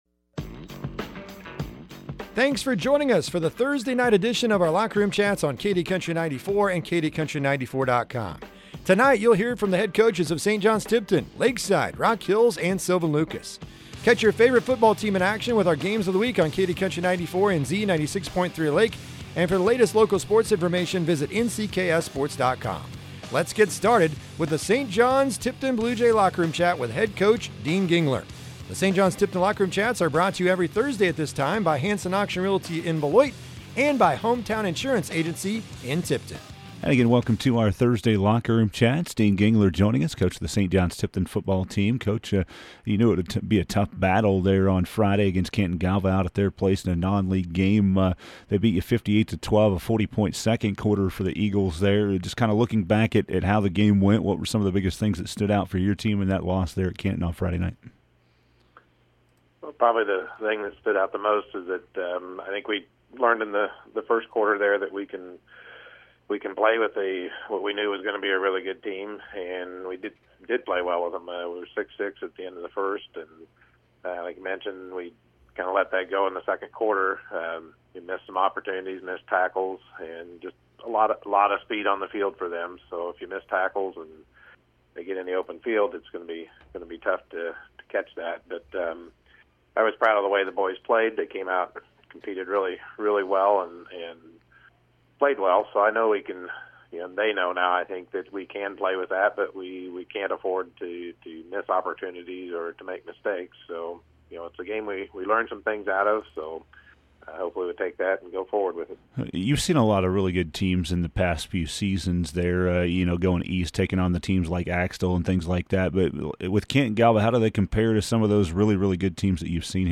are joined by the head football coaches